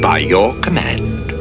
Supervisor Robot from Battlestar Galactica....